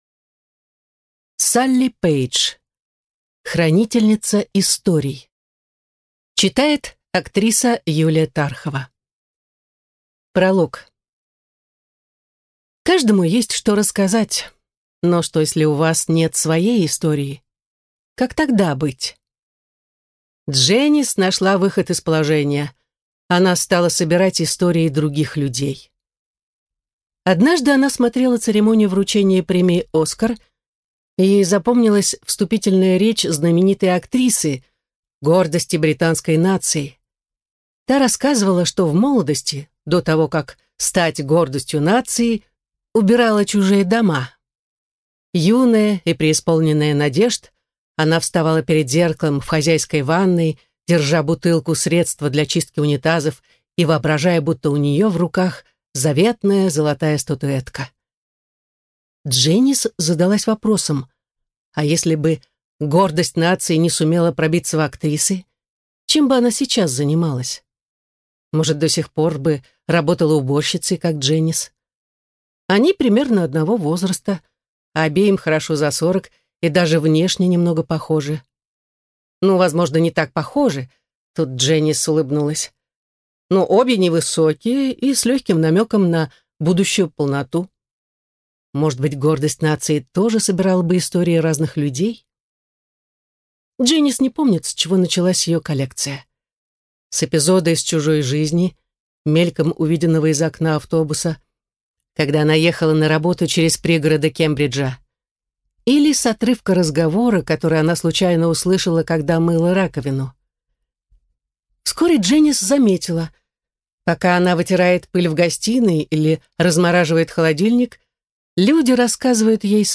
Аудиокнига Хранительница историй | Библиотека аудиокниг